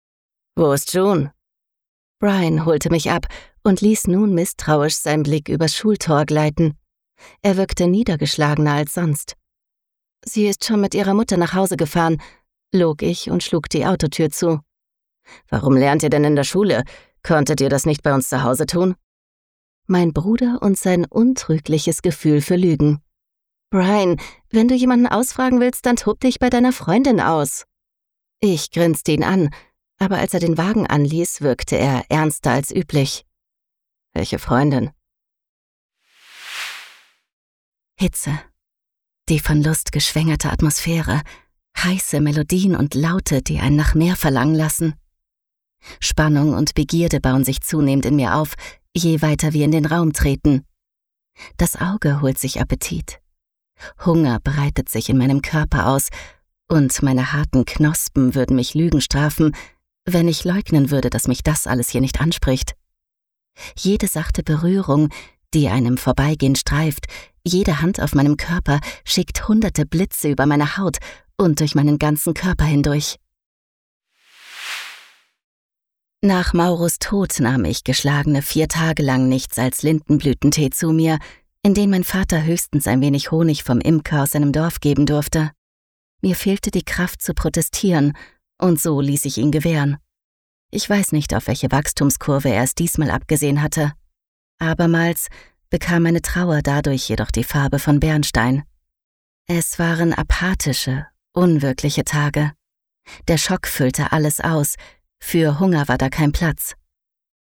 sehr variabel, dunkel, sonor, souverän, markant
Mittel minus (25-45)
Norddeutsch
3 Hörproben - Hörbuch
Audiobook (Hörbuch)